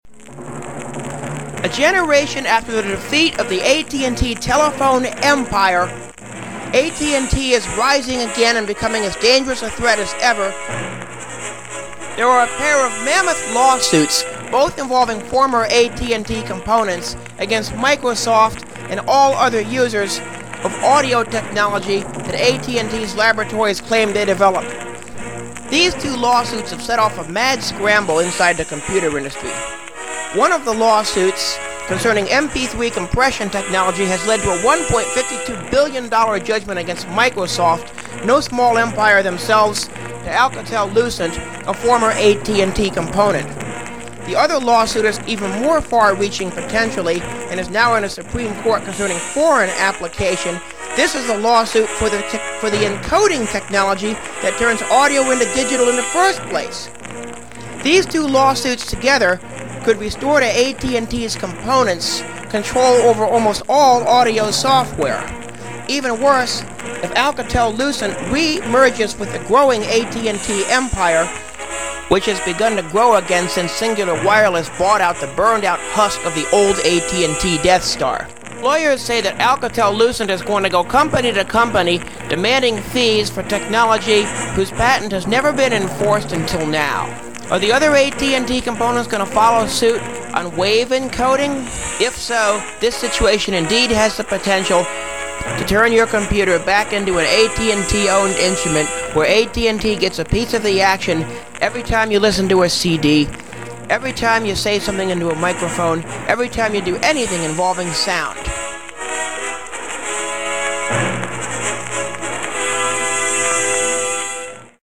unfortunately this is from the mp3 above so, it contains all the mp3 artifacts :(